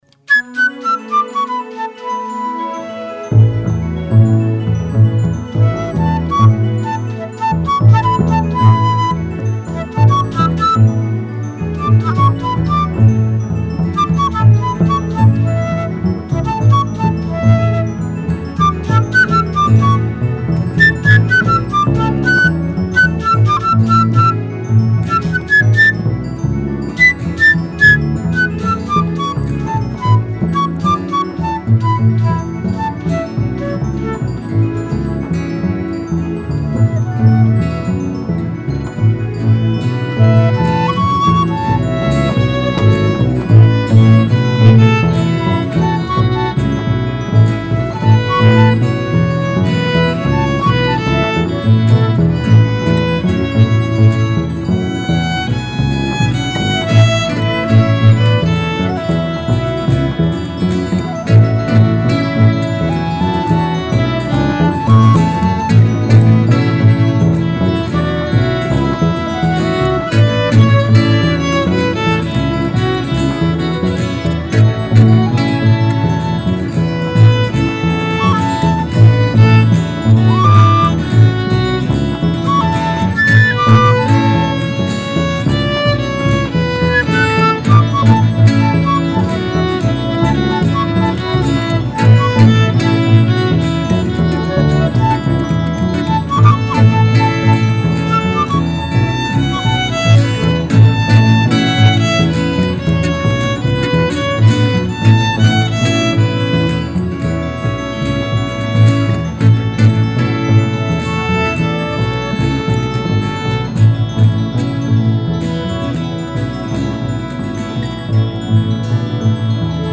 Casalgrande (RE) Nella splendida cornice del castello di Casalgrande Alto
alla chitarra
al contrabasso
al flauto traverso
al violino